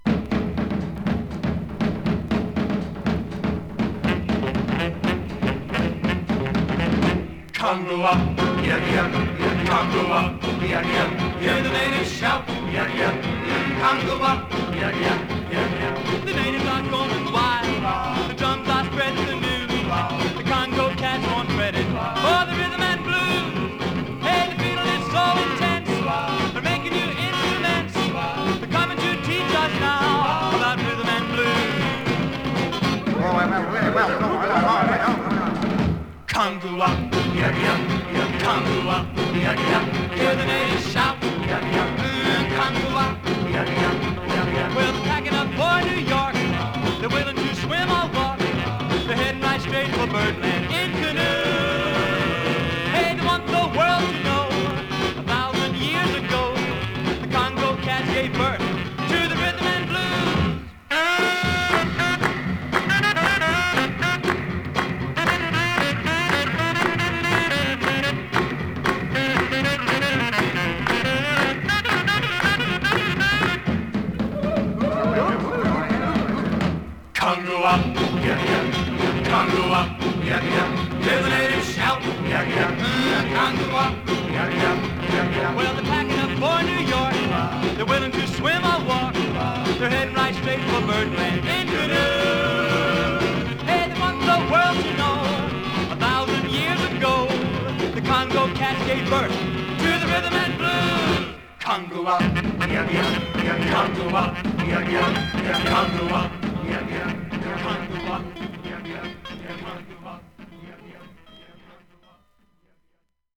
Вот еще одна оцифровка.